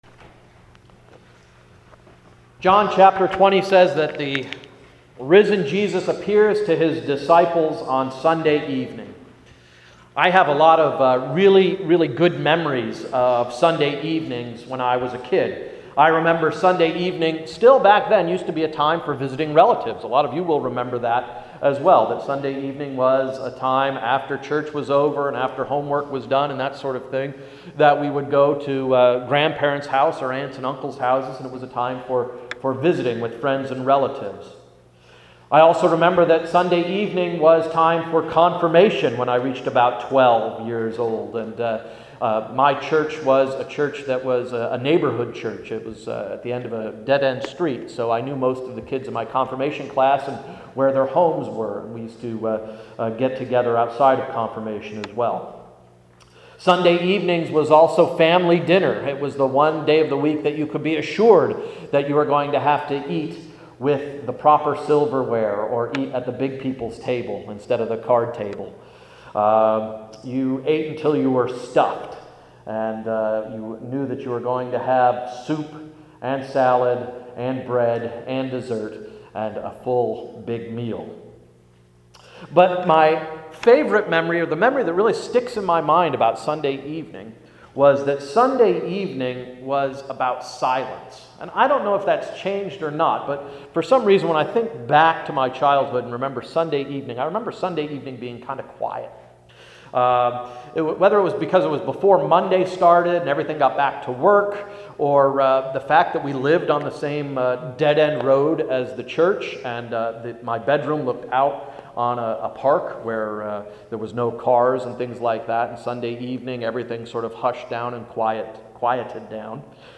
Sermon of April 15, 2012–“Sunday Evening”